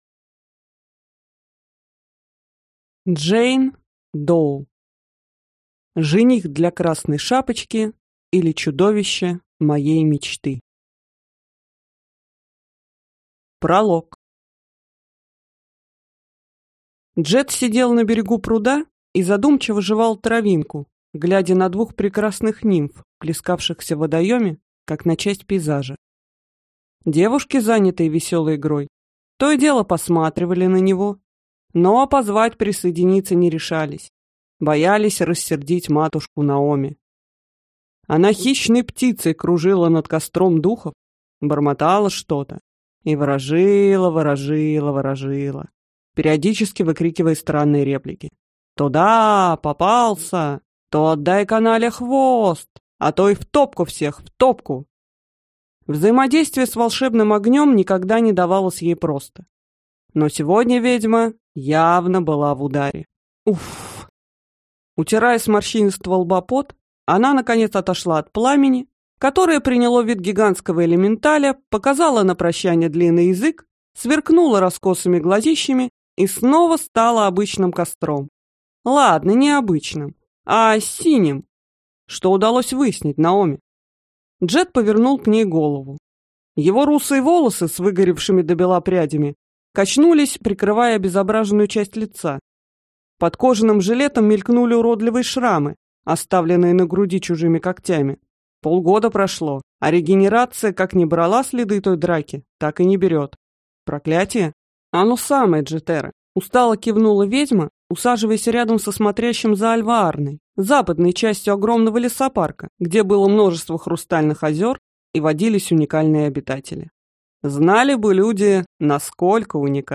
Аудиокнига Жених для Красной Шапочки, или Чудовище моей мечты | Библиотека аудиокниг
Прослушать и бесплатно скачать фрагмент аудиокниги